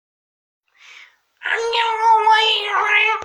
Sonido_raro_AA.wav